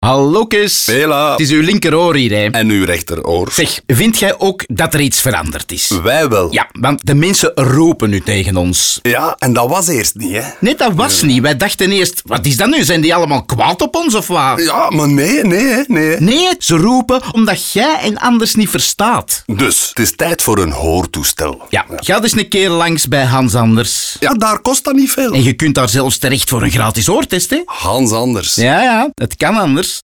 Om de awareness rond de hoortoestellen van Hans Anders te verhogen, creëerde mortierbrigade een radioconcept waarbij je eigen oren je aanspreken.